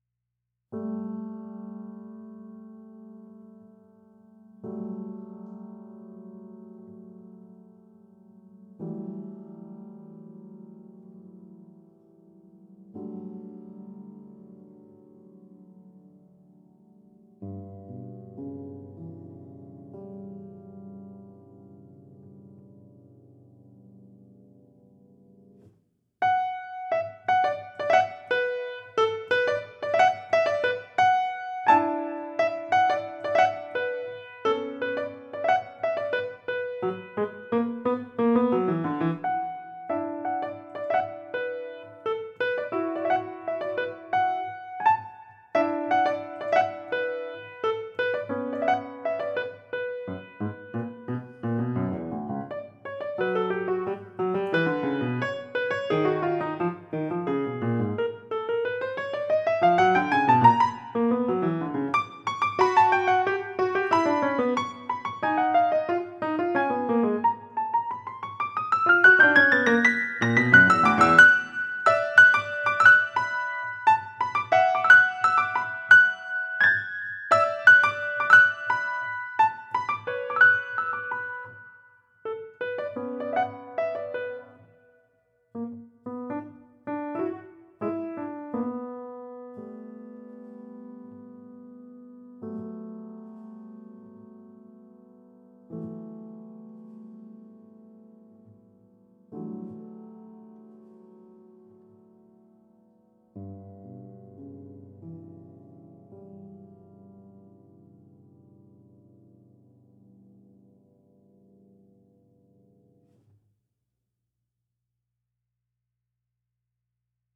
Calidad del archivo: Lossless
Piano, Música pedagogica